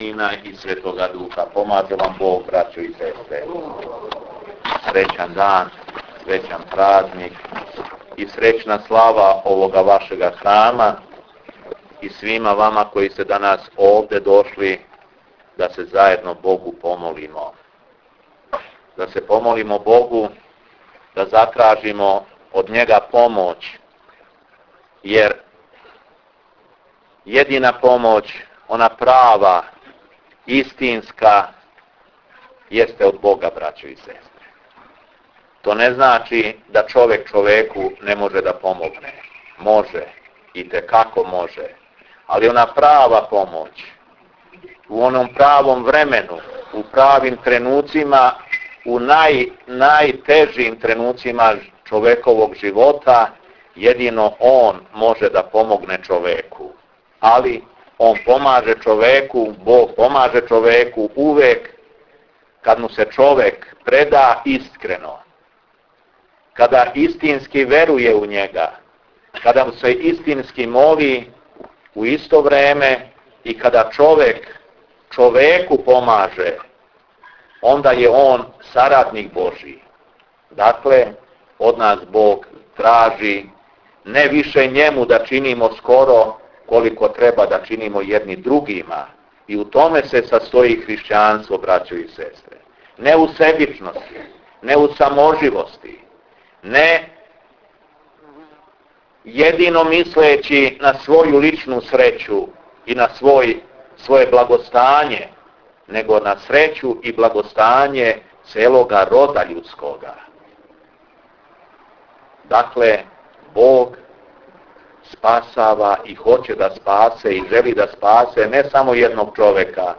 Беседа епископа шумсдијског Г. Јована у Станову - 09. август 2009. године